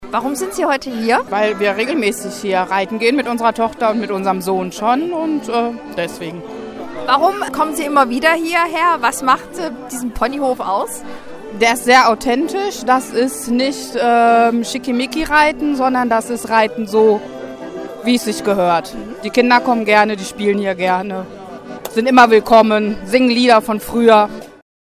Hier eine zufriedene Mama: